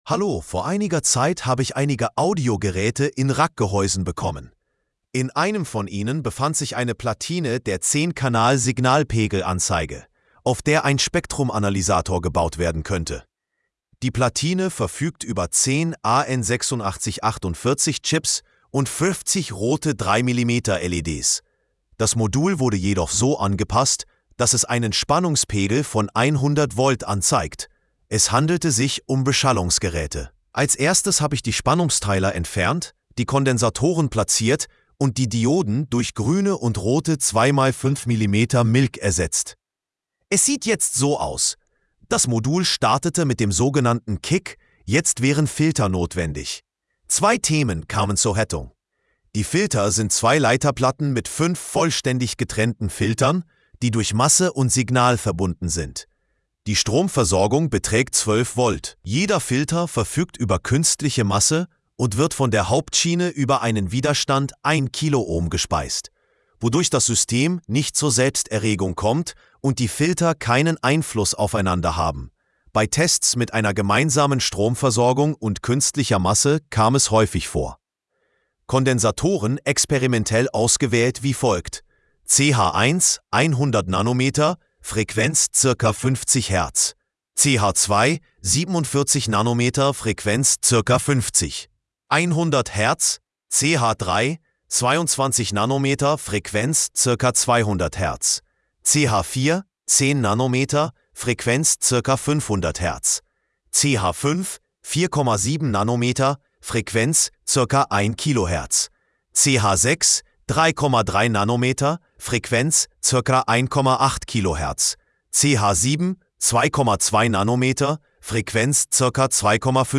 📢 Anhören (AI):